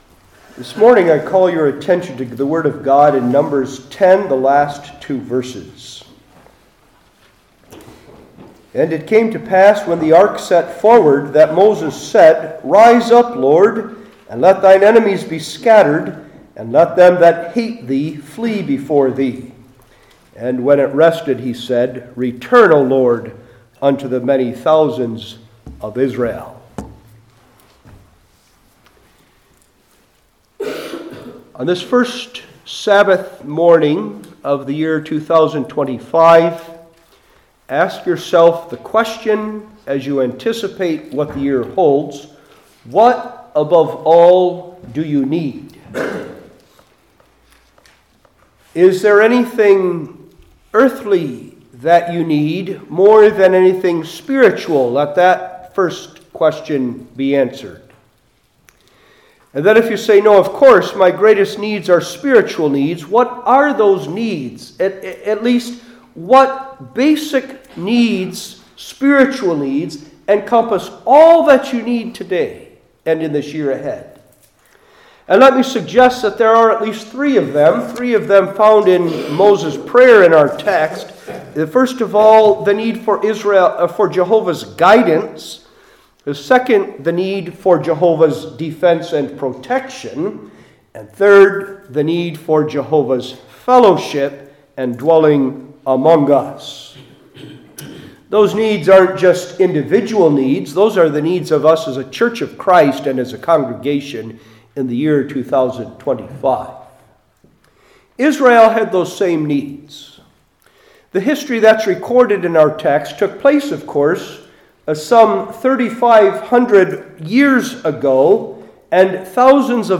Old Testament Individual Sermons I. The Prayer’s Content II.